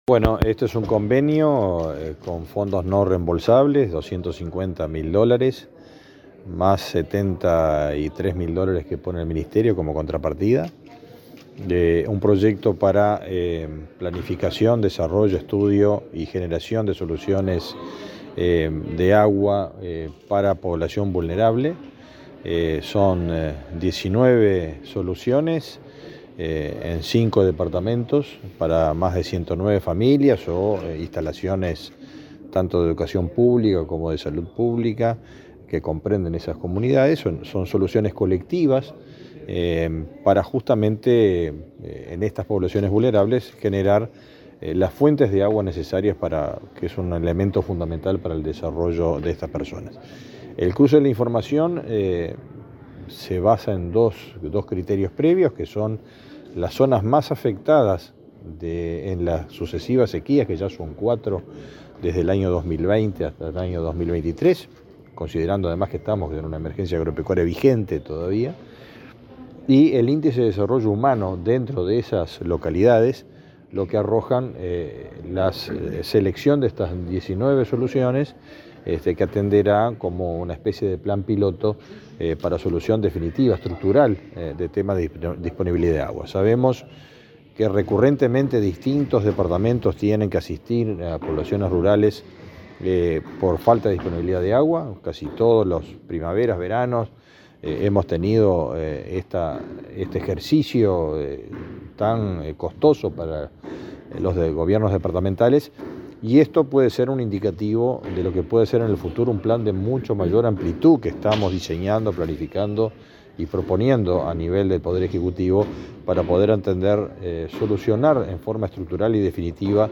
Declaraciones a la prensa del ministro de Ganadería, Fernando Mattos
Luego dialogó con la prensa.